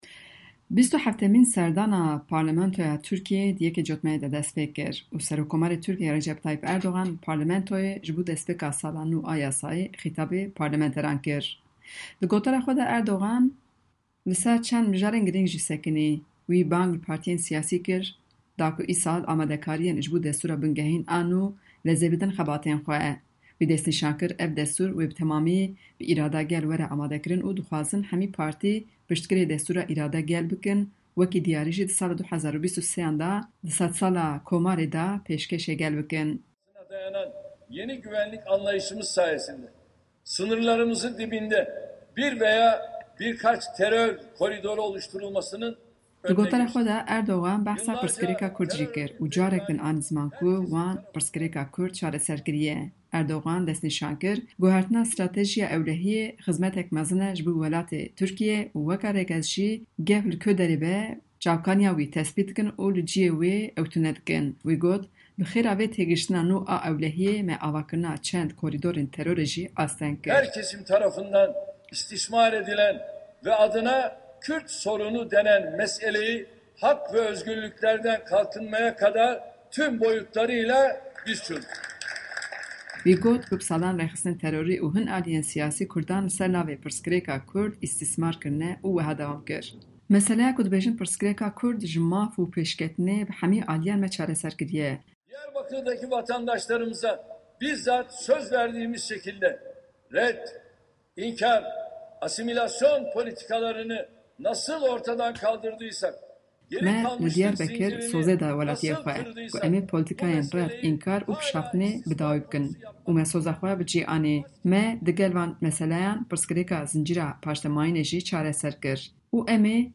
Bêtir agahî di raportên peyamnêrên me ji Enqere û Diyarbekirê de.